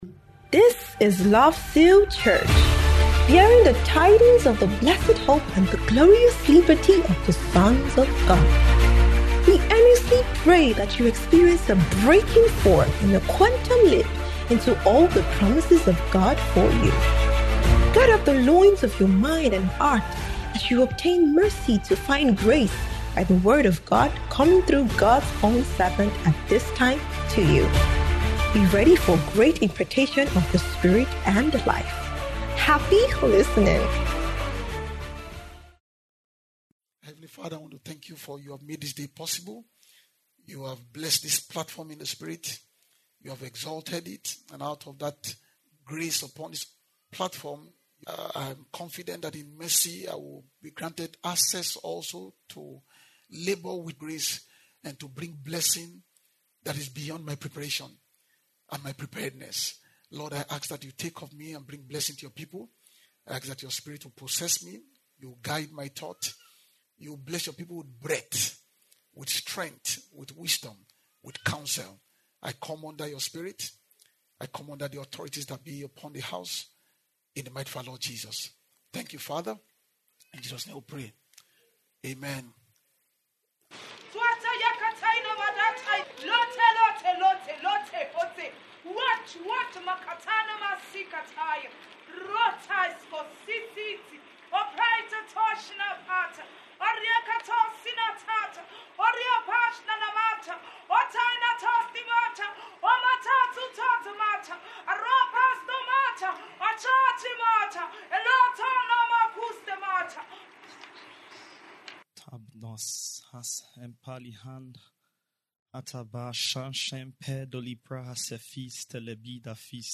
Spirit Word Ministration